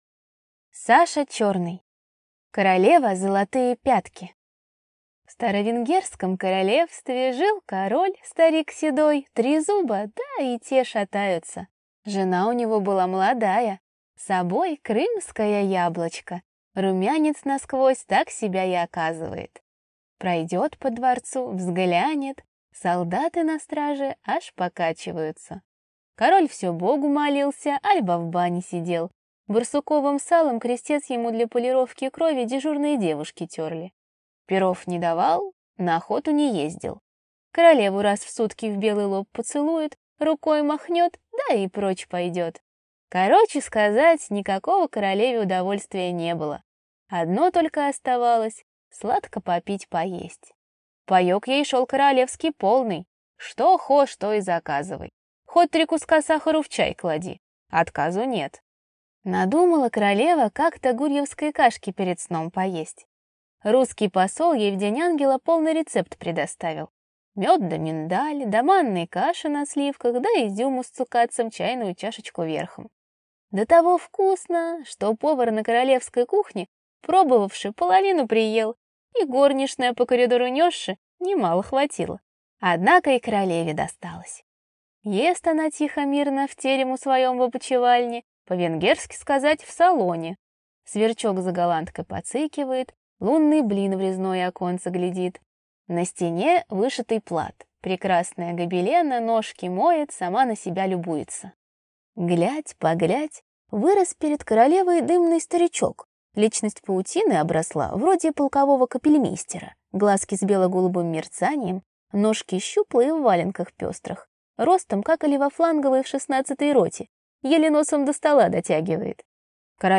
Аудиокнига Королева – золотые пятки | Библиотека аудиокниг